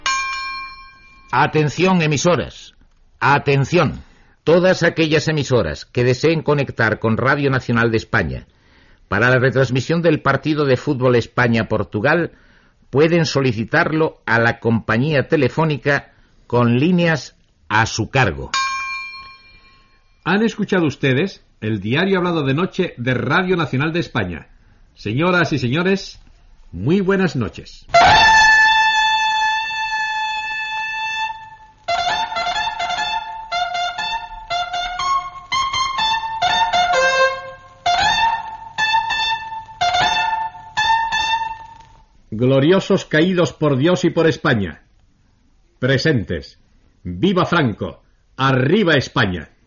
Sol·licitud de línies per transmetre el partit de futbol masculí entre Espanya i Portugal. Identificació del programa, toc militar de corneta, record als caiguts per Espanya, visca Franco i "arriba España".
Informatiu